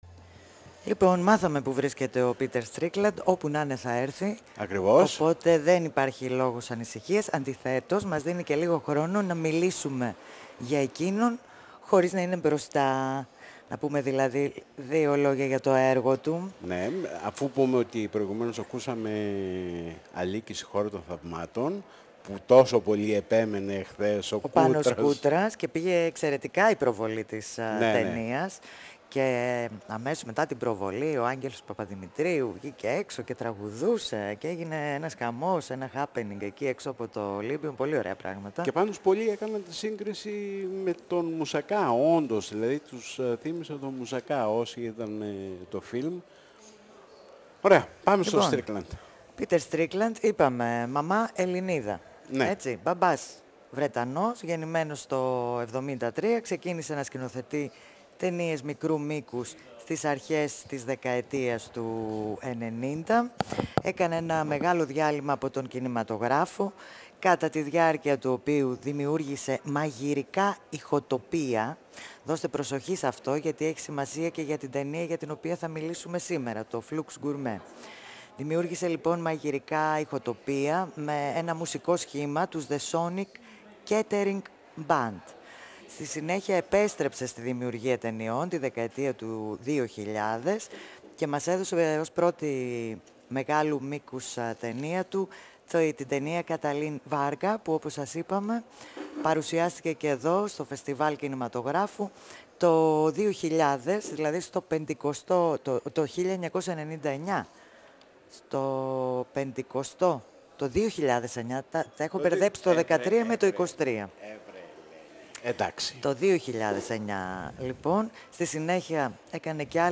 Ο σπουδαίος Βρετανός σκηνοθέτης Πίτερ Στρίκλαντ, μιλάει εφ’ όλης της ύλης για τον κινηματογράφο, τη μουσική, τις γεύσεις.
Συνεντεύξεις